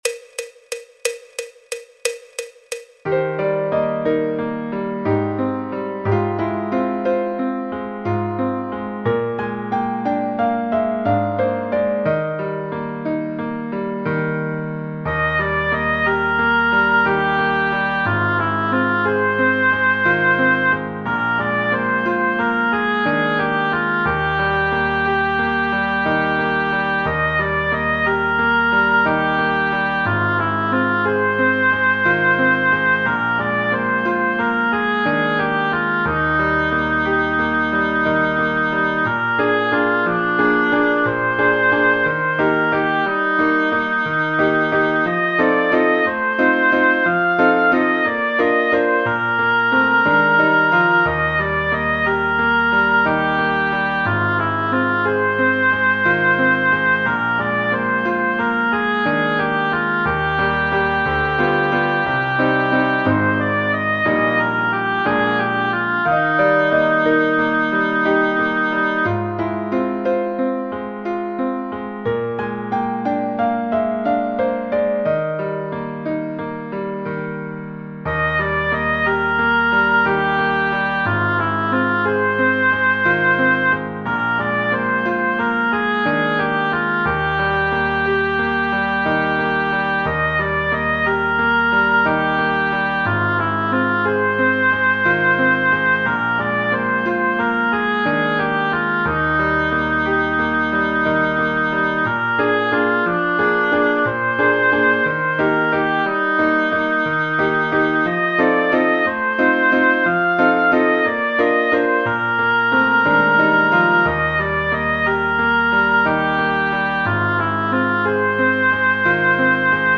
El MIDI tiene la base instrumental de acompañamiento.
Música clásica